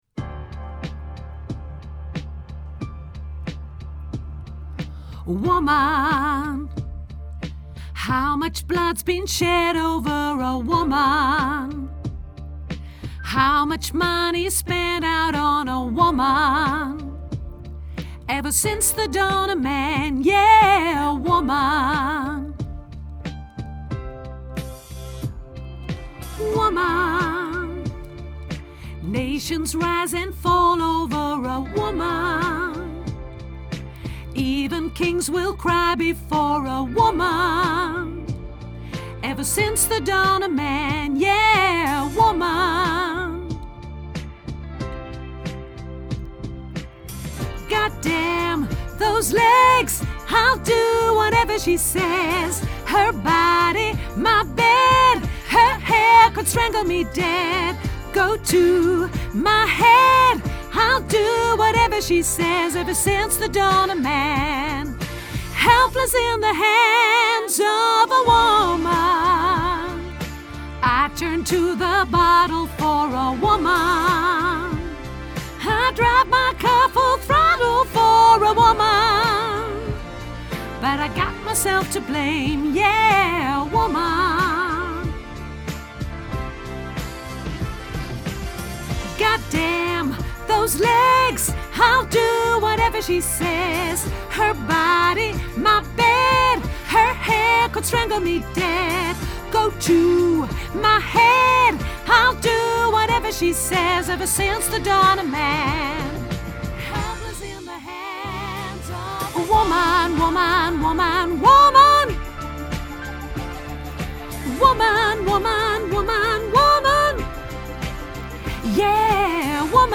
hoog sopraan
Woman Hoog Sopraan Grote Koor Mp 3